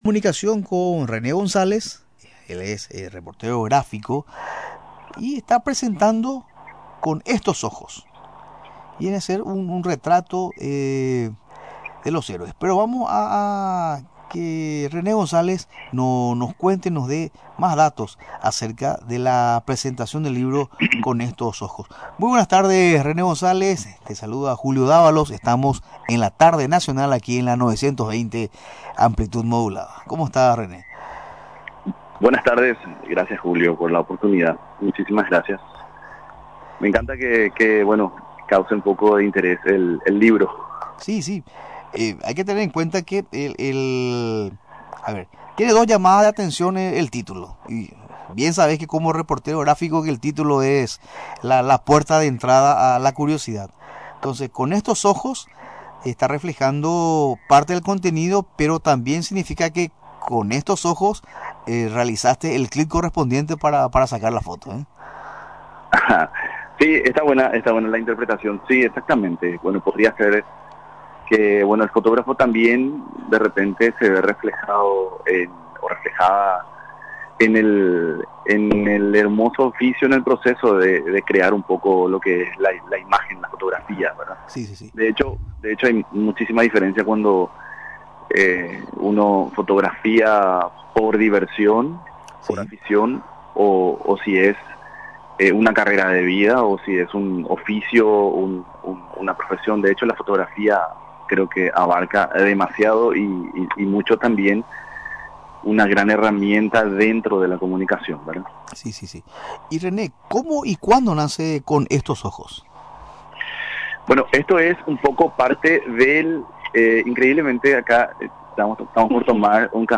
Durante la entrevista en Radio Nacional del Paraguay, explicó captura el proceso de cambio que se produce en la región occidental del Paraguay, a lo largo de los años.